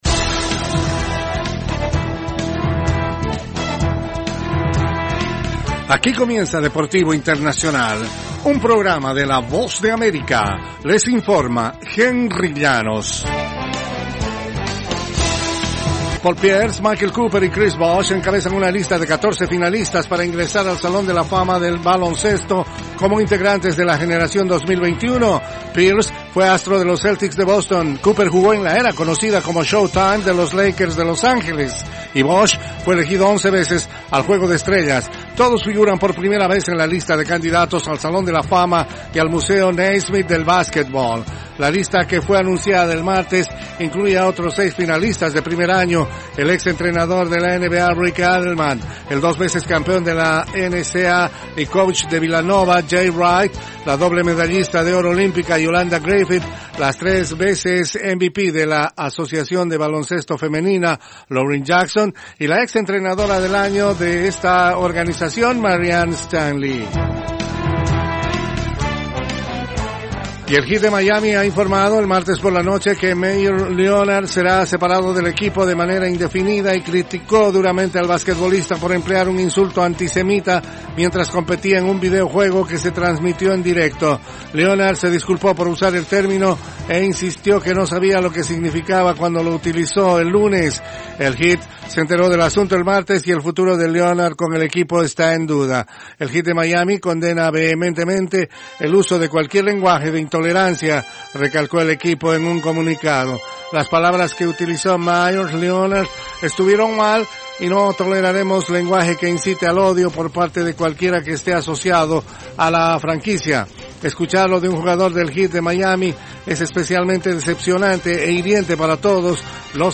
Las noticias deportivas llegan desde los estudios de la Voz de América en la voz de